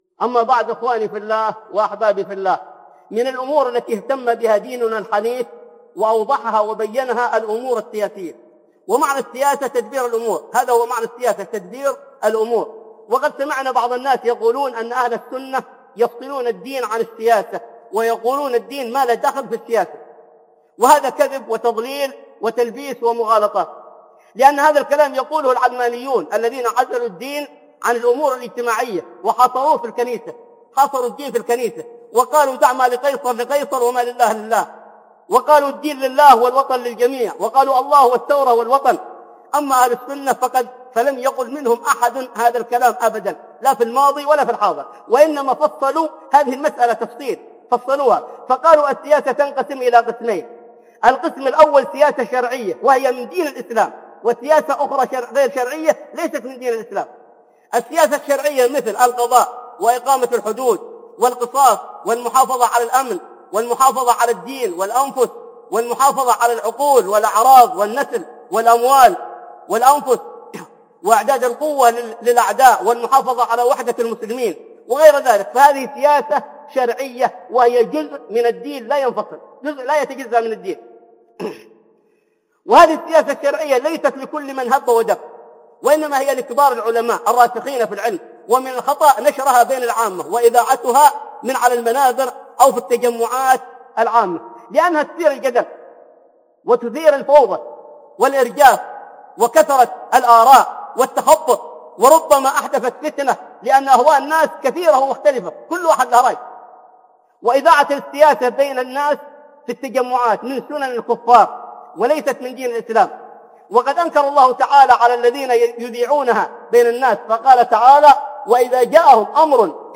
السياسة الشرعية والسياسة الغربية - خطب